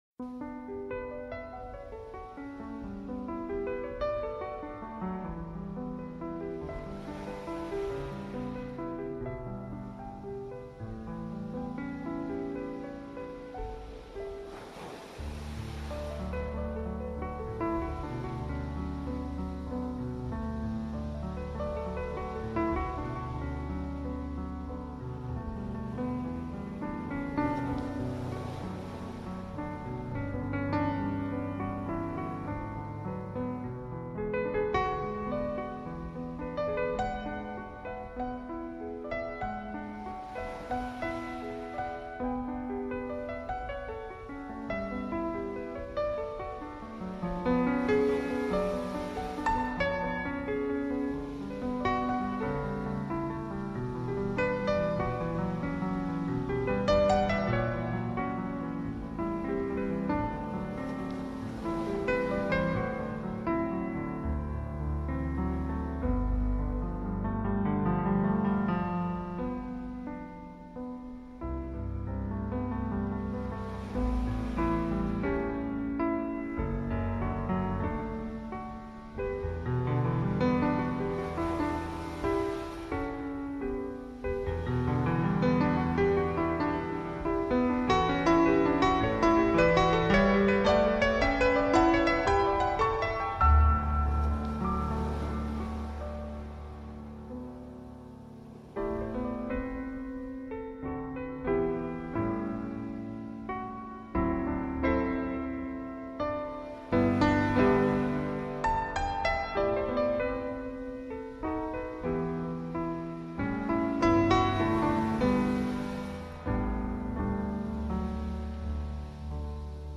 Debussy – Piano instrumental para estudiar y concentrarse